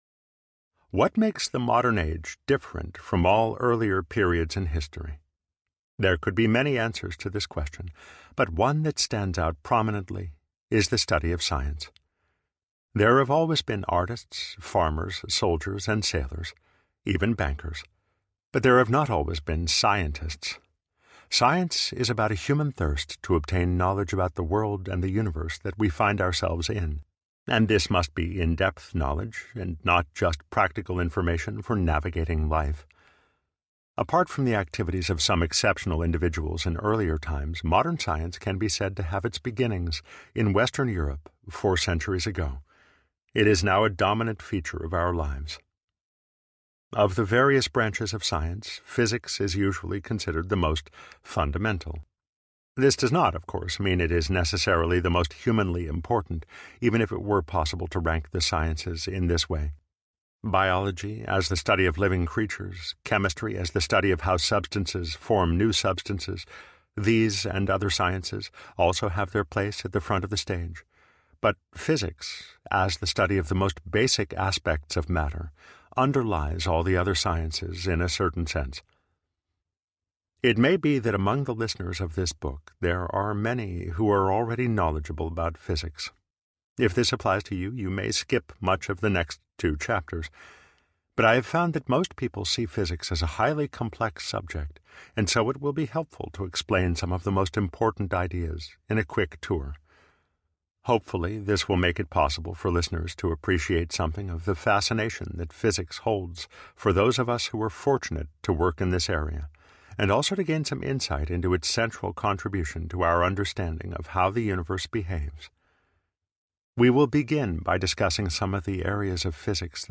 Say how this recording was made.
8.5 Hrs. – Unabridged